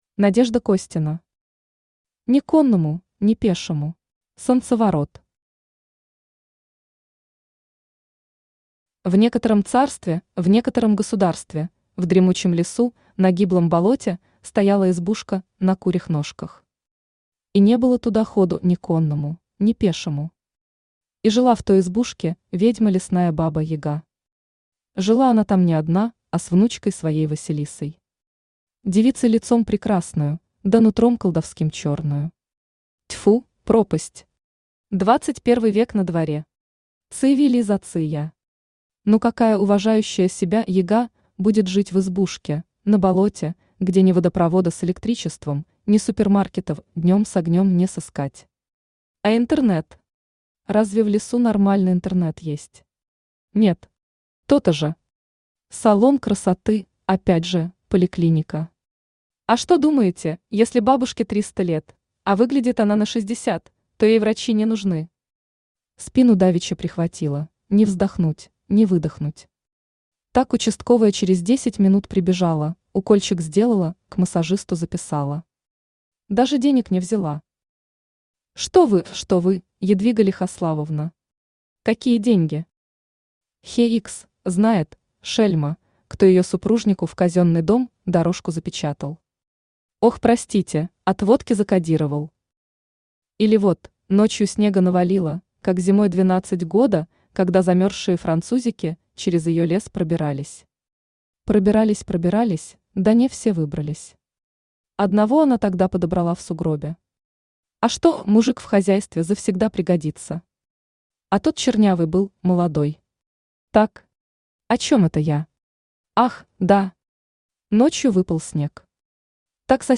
Аудиокнига Ни конному, ни пешему…
Автор Надежда Костина Читает аудиокнигу Авточтец ЛитРес.